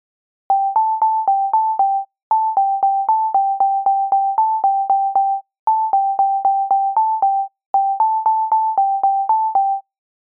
Rhinoで、sine波の一つだけのOscillatorでPitchを+12にし、Filterなし、Effectなしの状態をベースとして、
各巻ごとにOscillatorのlevel envelopeのみを変えています。
先頭に0.5秒の無音部を入れています。
巻四の音色